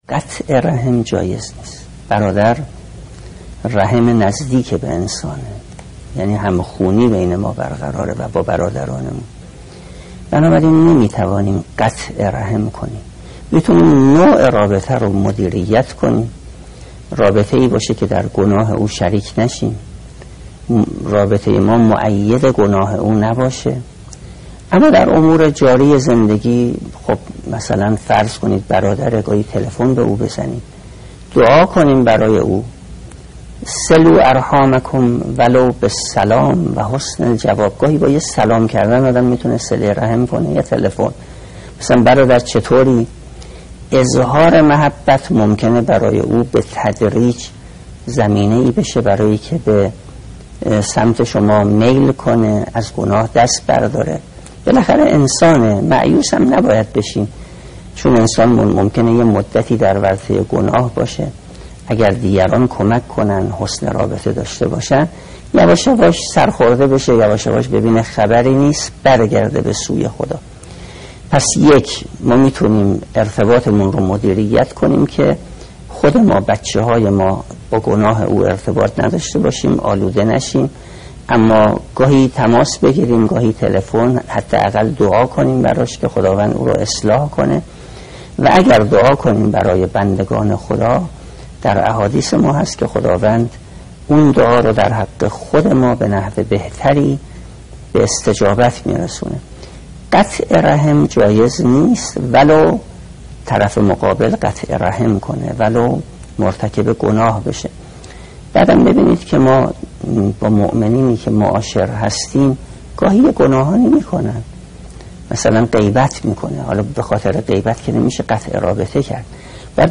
مجموعه پادکست «روایت بندگی» با کلام اساتید بنام اخلاق به کوشش ایکنا گردآوری و تهیه شده است که سی‌وهفتمین قسمت این مجموعه با کلام استاد سید محسن میرباقری با عنوان «صله رحم با اقوام گناهکار» تقدیم مخاطبان گرامی ایکنا می‌شود.